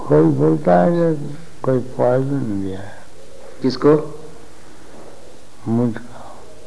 USA (VNN) - The following hindi conversion can be found in Vol. 36, Conversations Books, Page 354 - Room Conversation Vrindavan Nov., 8, 1977.